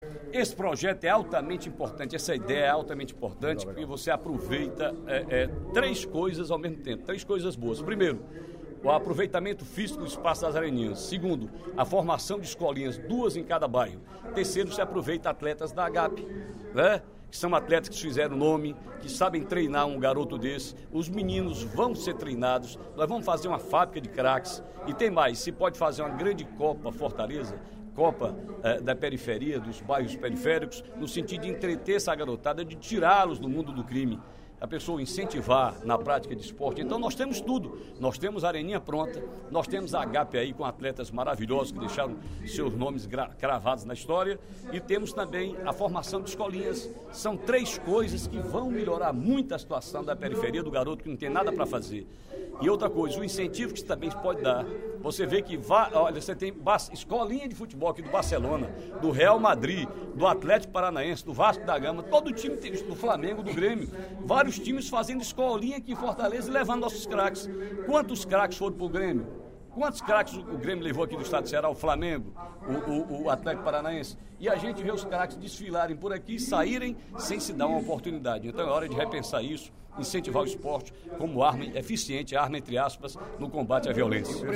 O deputado Ferreira Aragão (PDT) sugeriu, durante o primeiro expediente da sessão plenária desta terça-feira (21/03), um projeto que aproveite as areninhas de Fortaleza para a formação de craques.
Em aparte, o deputado Jeová Mota (PDT) lembrou que, quando secretário do Esporte do Estado, um projeto que cria escolinhas esportivas e financia os estudos do atleta foi criado.